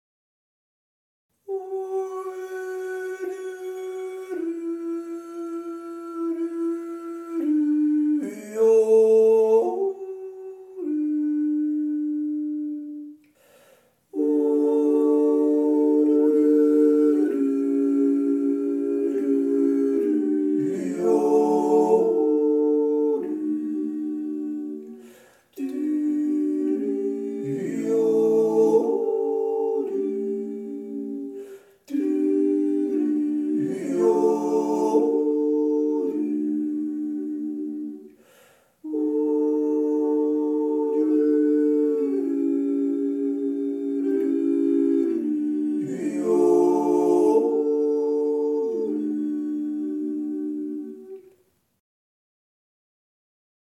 dreistimmig Jahresendjodler (Hannes Fohrer)